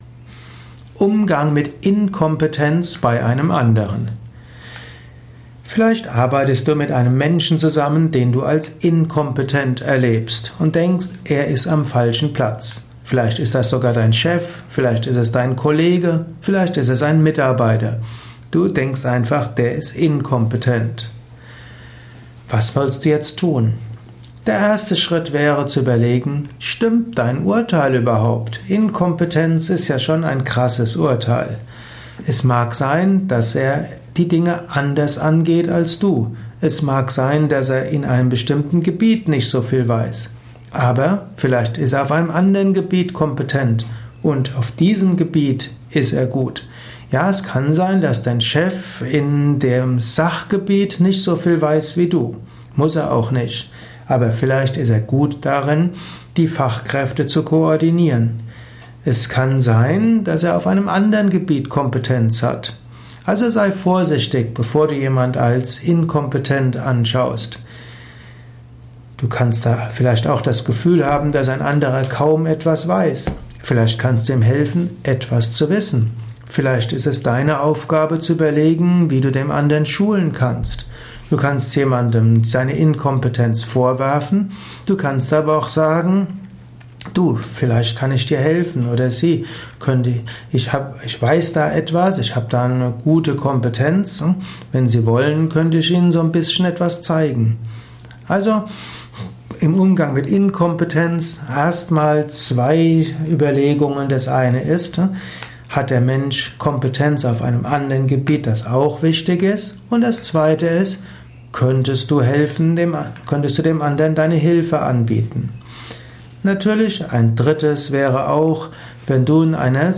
Eine Abhandlung mit Inhalt Inkompetenz anderer. Erfahre einiges über Inkompetenz in dieser kurzen Abhandlung, einen spontanen Audiovortrag.